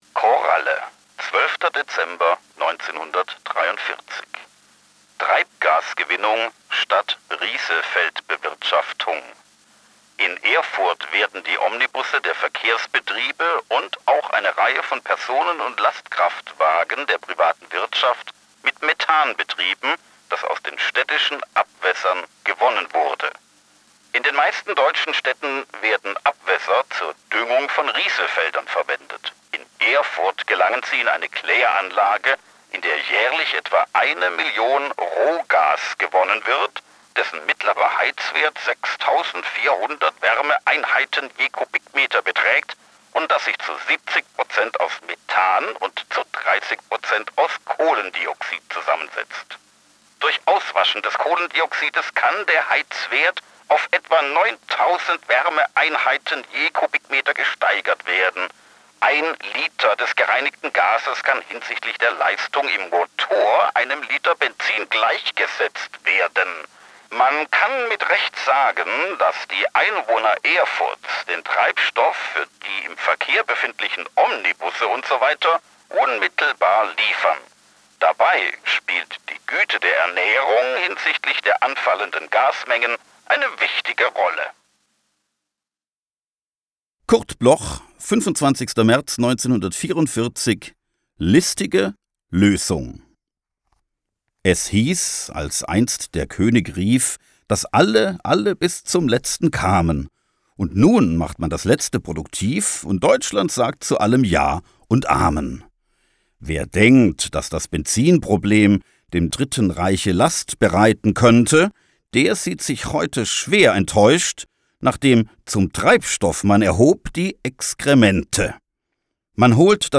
Recorded at Tonstudio Kristen & Schmidt, Wiesbaden